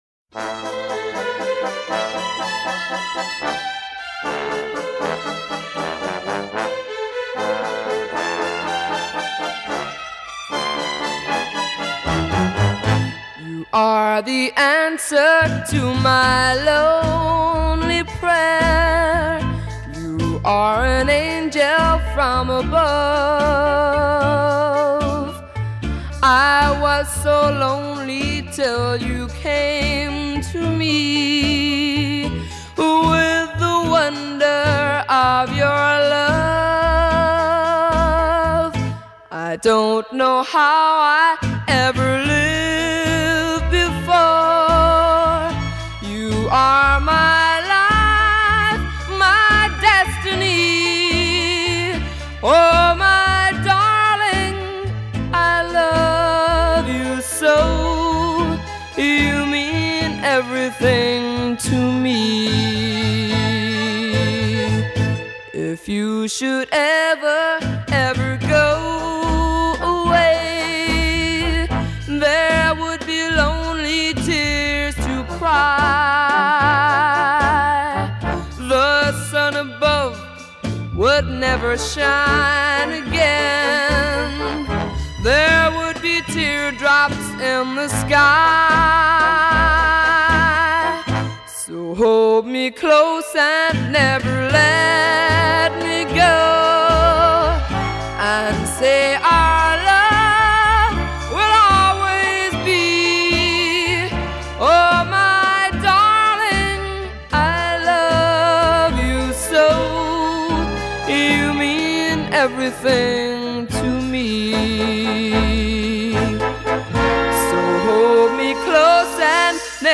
今天，我的随机怀旧心情令我选择了一个我当年误会是男歌手的女歌手.